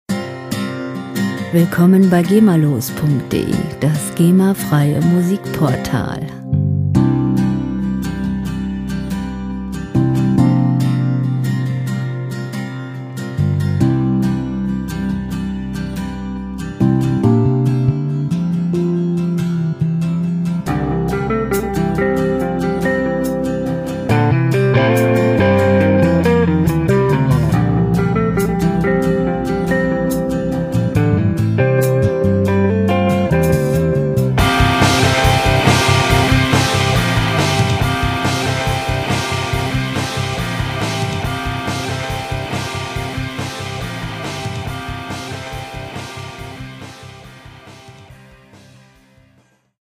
Rockmusik - Legenden
Musikstil: Rock
Tempo: 140 bpm
Tonart: C-Dur
Charakter: ehrlich, ungekünstelt